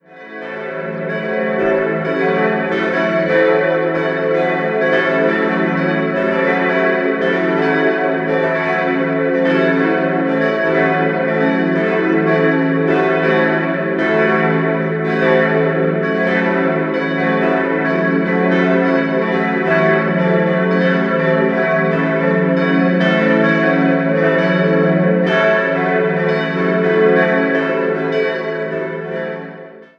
-plastiken stammen von dem Nürnberger Bildhauer Walter Ibscher. 4-stimmiges Geläute: e'-fis'-a'-cis' Die Glocken wurden im Jahr 1964 von der Firma Rincker in Sinn gegossen.